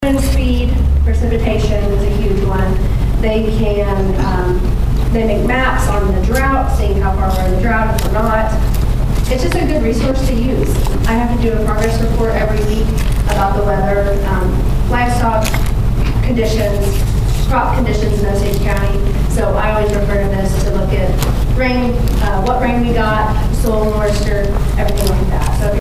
Wednesday's lunch and learn session took place at the community center in Pawhuska.